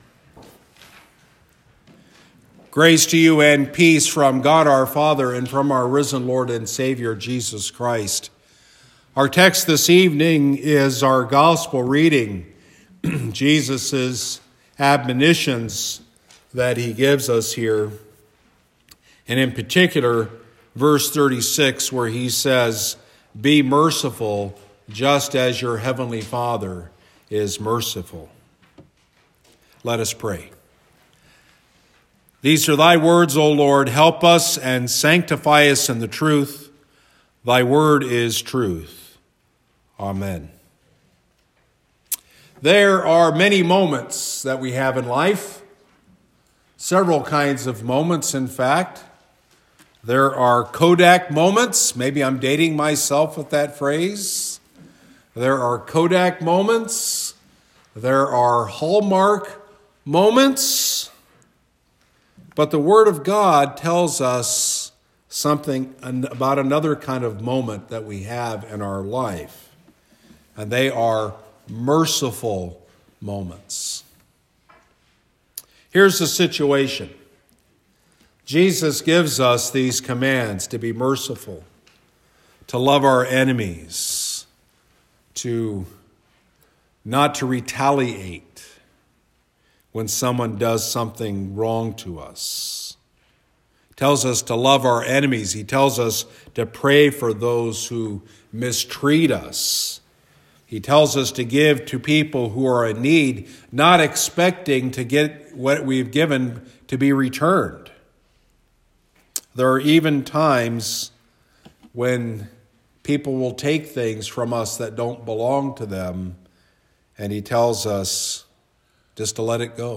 Wednesday evening sermon delivered at Christ Lutheran Church of Chippewa Falls, WI on 27 February 2019.